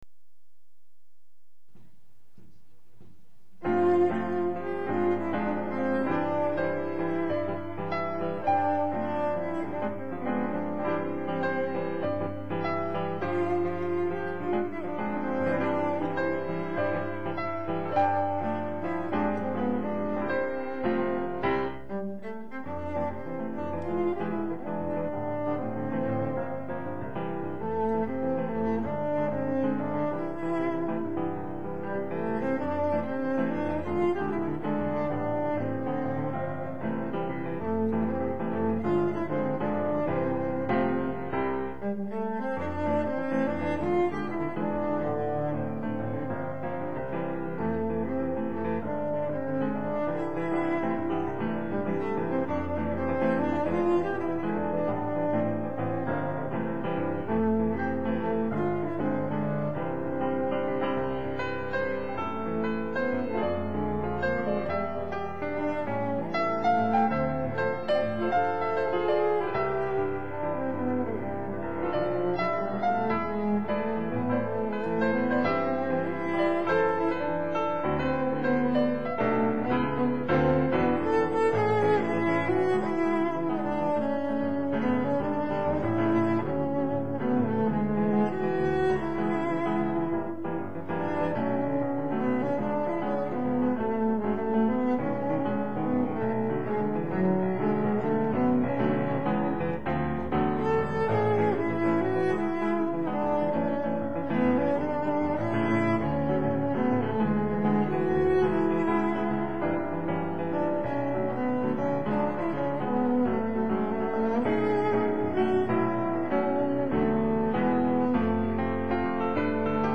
음질은 저번꺼보다 떨어지는거 같네요